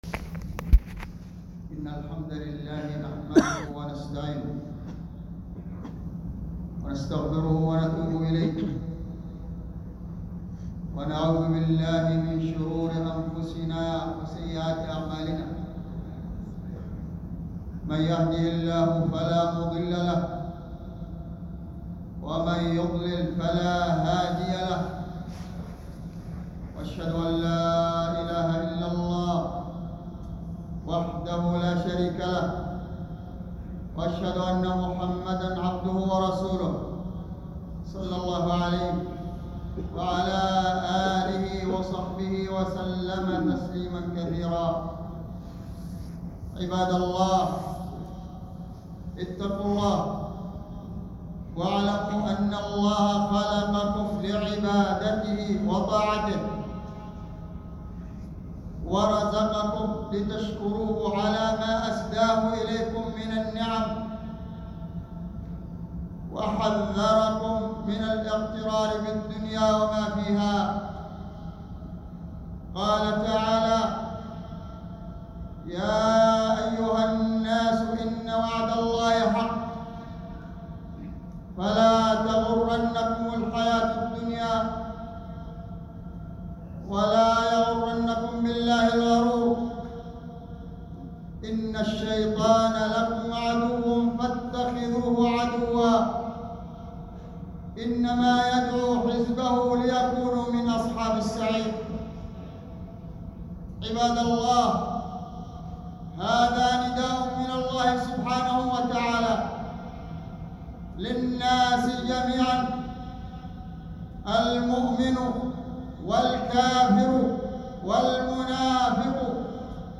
خطبة جمعة بعنوان عدم الاغترار بالدنيا